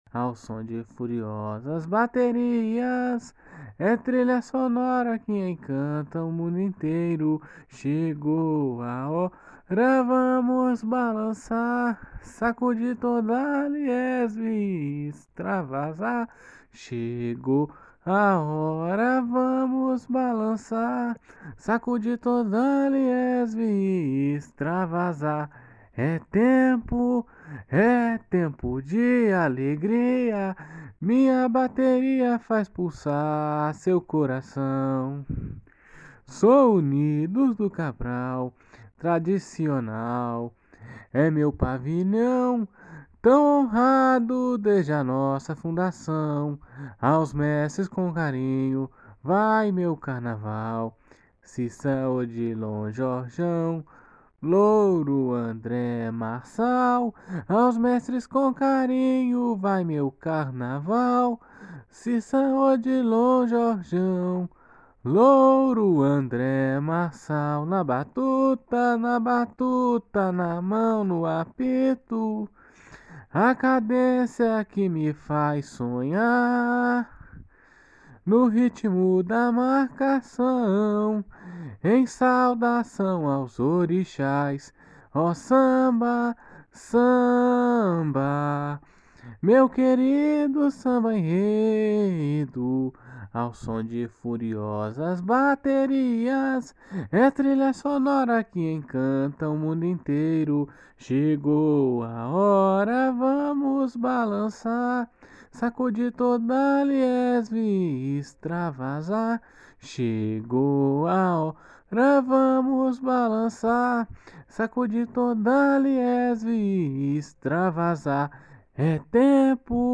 Samba  03